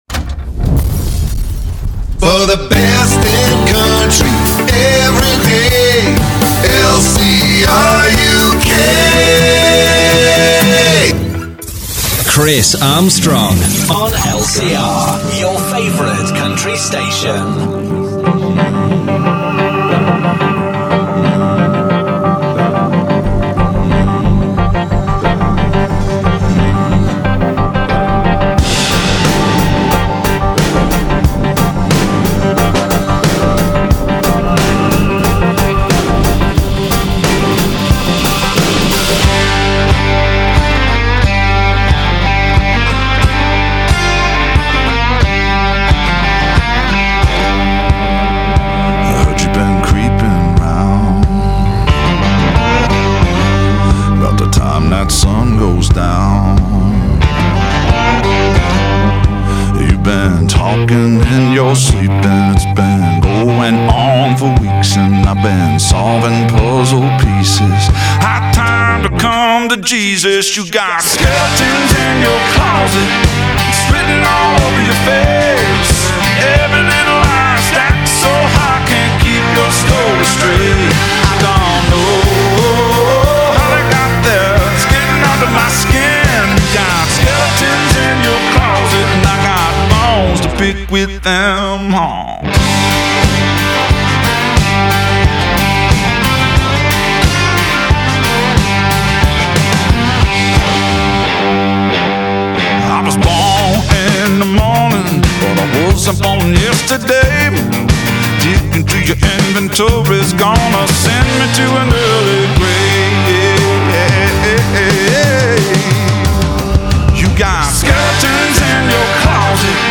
The station is airing 24/7 with the widest range of country music you’ll find on any one station, anywhere in the world!